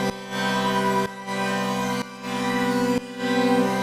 GnS_Pad-MiscB1:2_125-C.wav